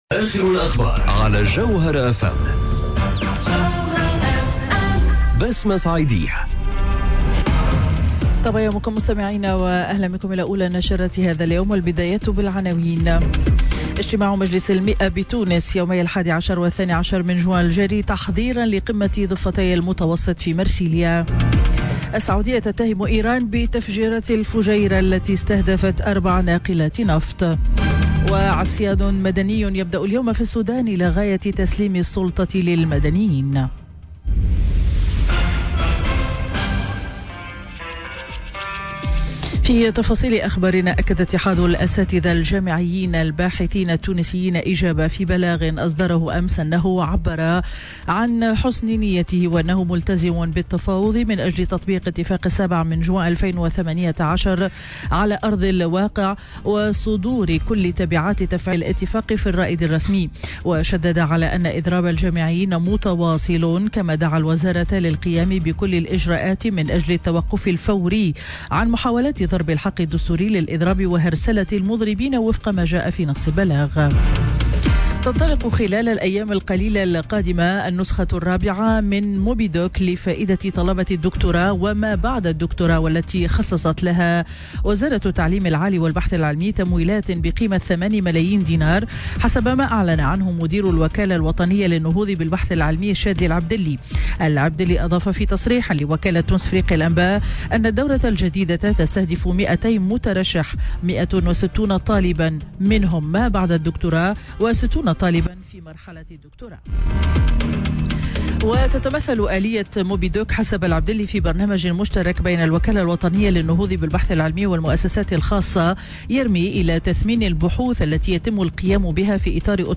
نشرة أخبار السابعة صباحا ليوم الأحد 09 جوان 2019